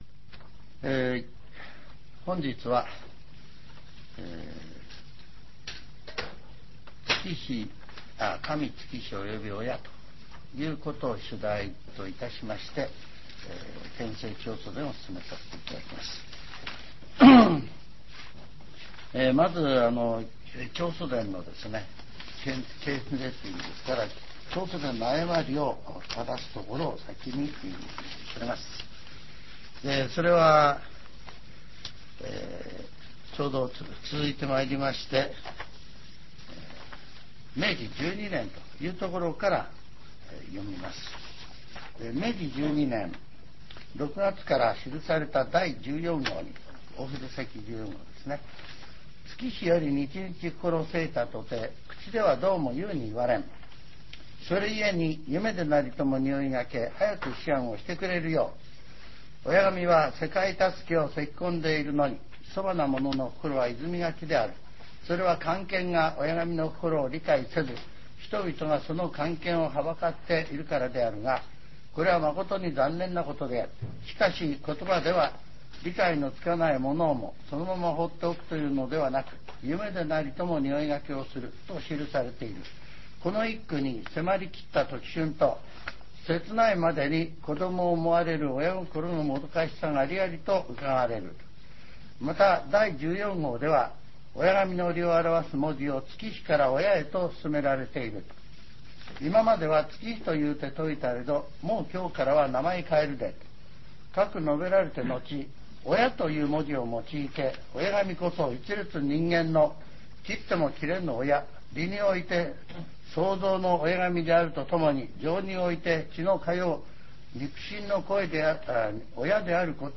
全70曲中30曲目 ジャンル: Speech